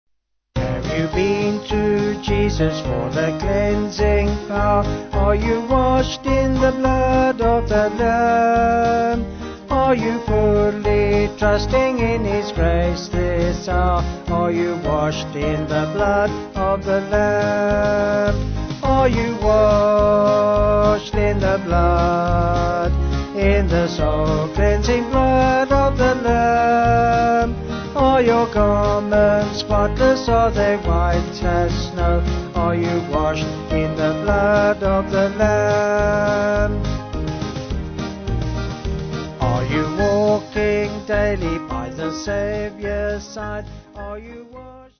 Vocals and Band